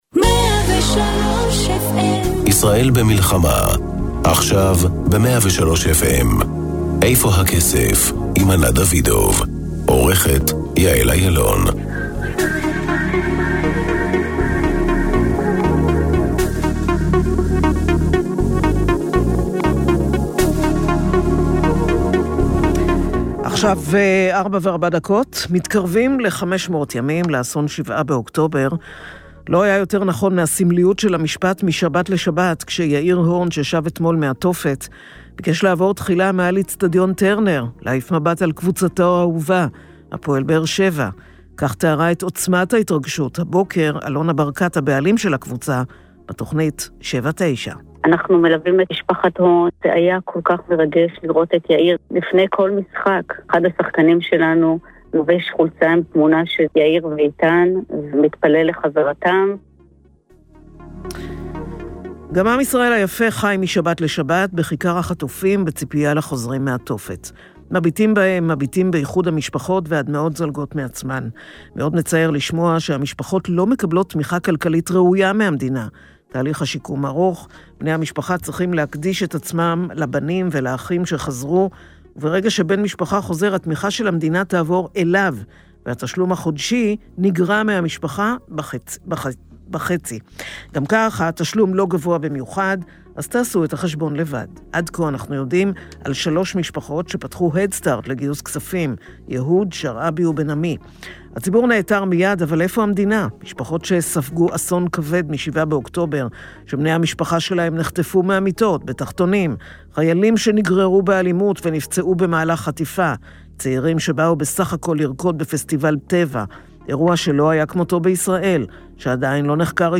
לצד הכותרות הכלכליות, מביאה התוכנית ראיונות עם בכירי המשק, תחקירי צרכנות פיננסית, טורים אישיים שתוקפים את נושאי הכלכלה מזוויות שונות, ופינות בנושאי טכנולוגיה ואפילו טיולים ופנאי.